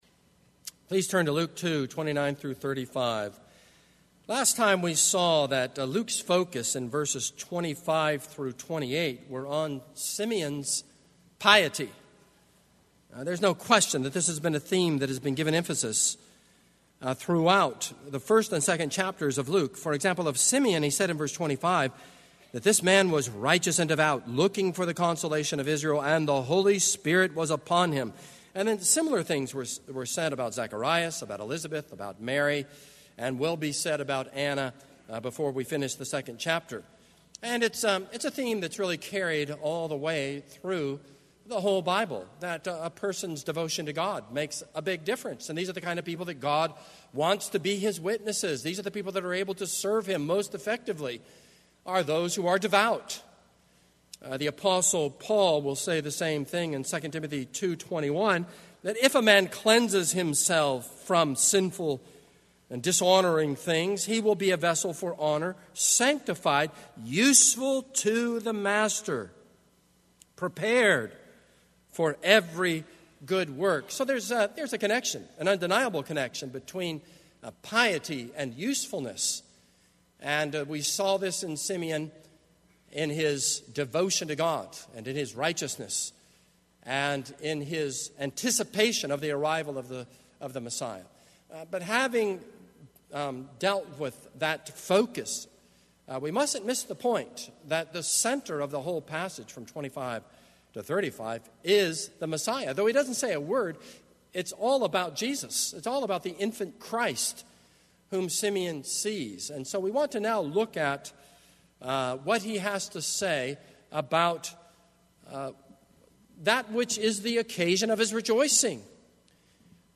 This is a sermon on Luke 2:25-35.